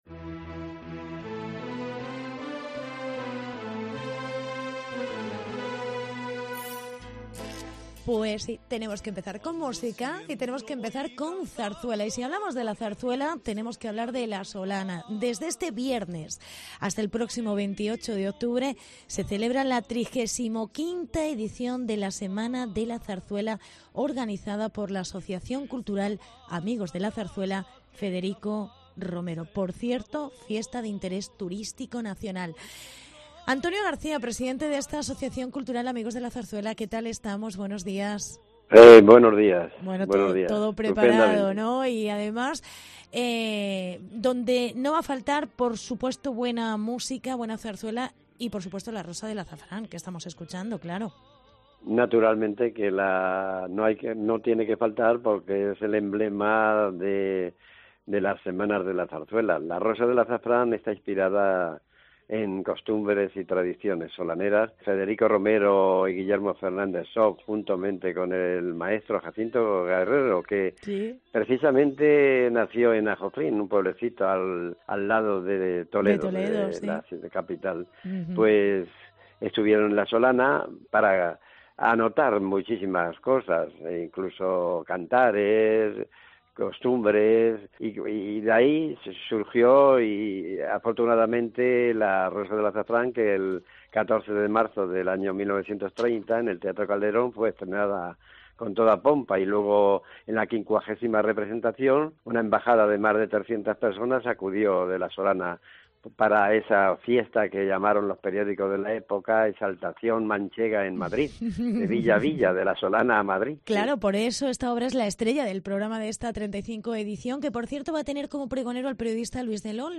35 Semana de la Zarzuela en la Solana. Entrevista